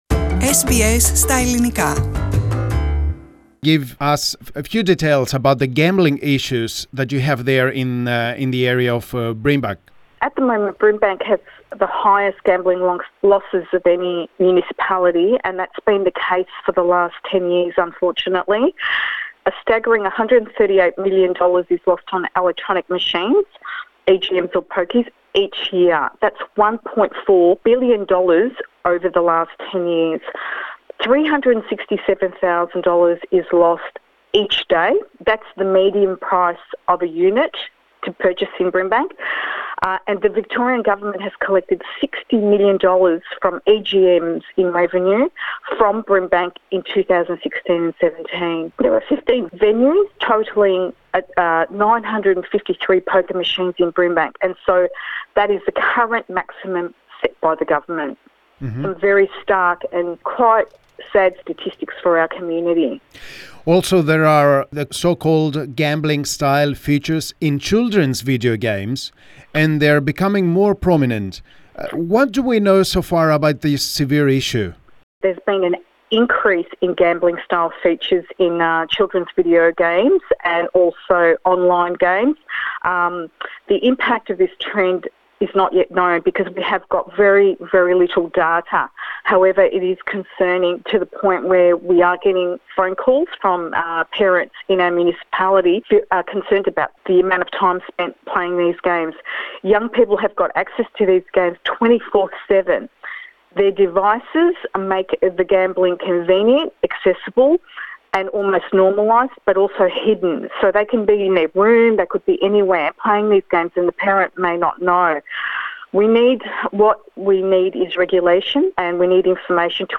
Η δημοτική σύμβουλος Βιργινία Τάχου ξεκίνησε προσπάθεια ευαισθητοποίησης στο ζήτημα του τζόγου που τώρα αγγίζει και τα παιδιά. Η κ. Τάχου μίλησε στο Ελληνικό Πρόγραμμα.
Ακούστε εδώ τη συνέντευξη με τη Δημοτική Σύμβουλο Brimbank Βιργινία Τάχου στα Ελληνικά: